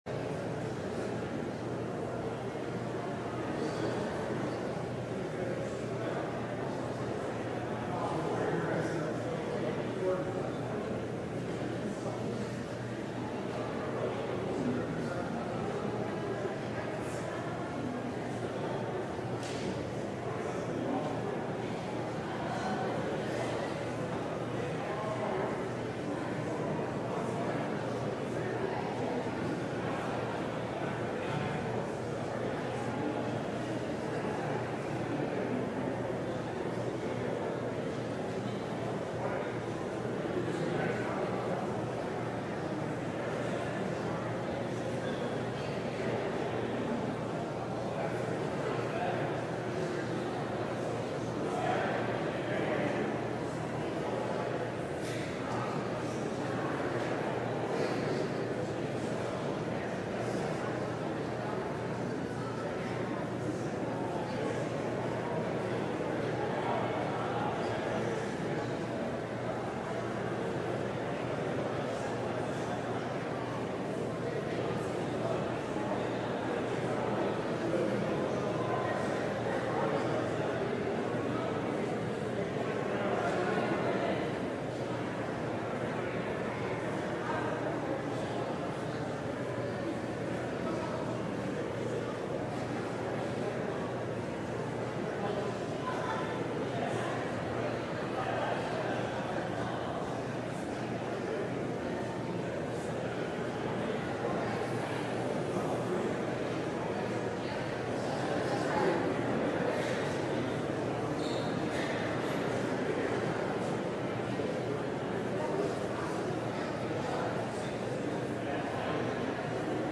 LIVE Morning Worship Service - Gentle Mercy: a Nap, a Meal, and a Walk
Congregational singing—of both traditional hymns and newer ones—is typically supported by our pipe organ. Vocal choirs, handbell choirs, small ensembles, instrumentalists, and vocal soloists provide additional music offerings.